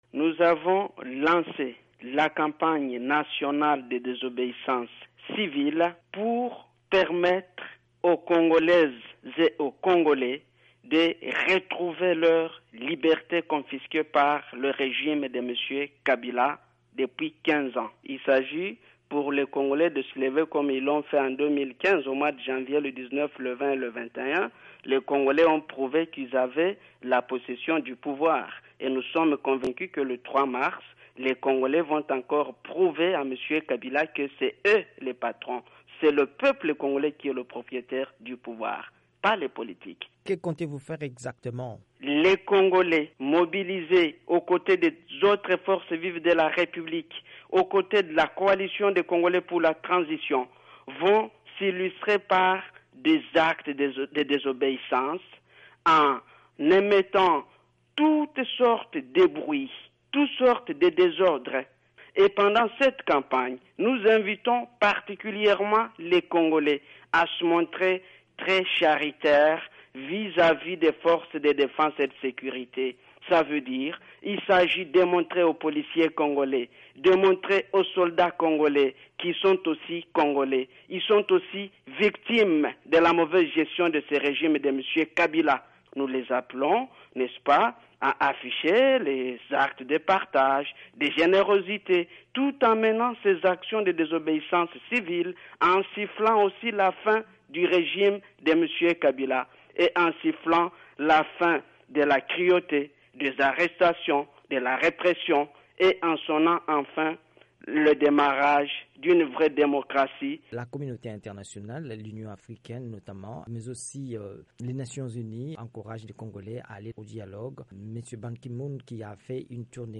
interviewé par VOA Afrique.